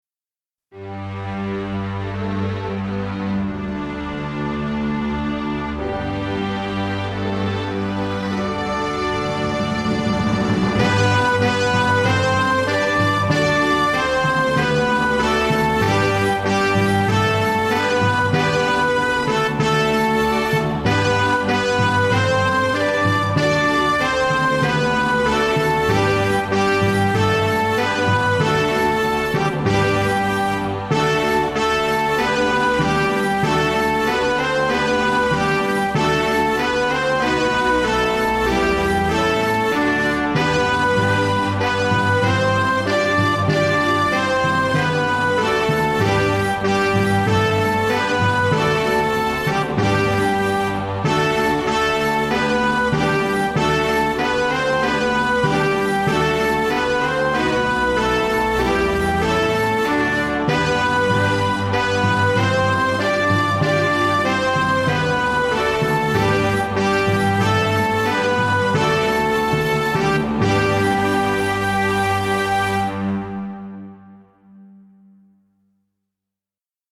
Vredeslied: iets langzamer dan op de oefen-cd